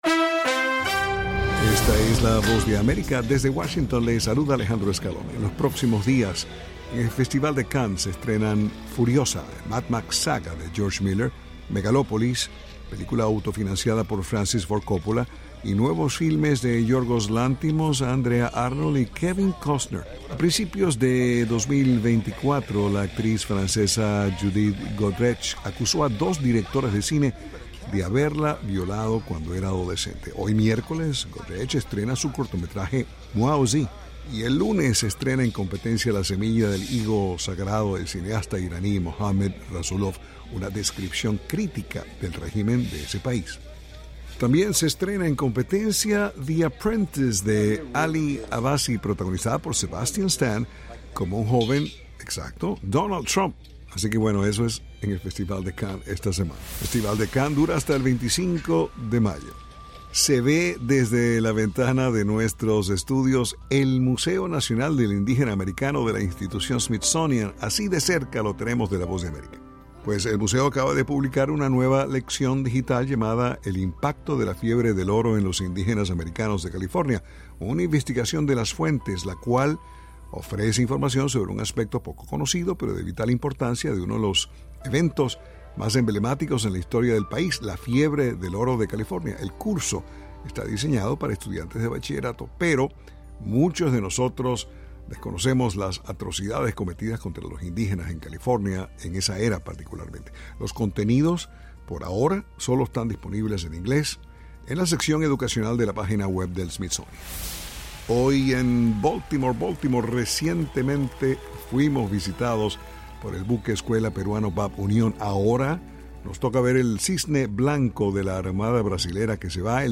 noticias del espectáculo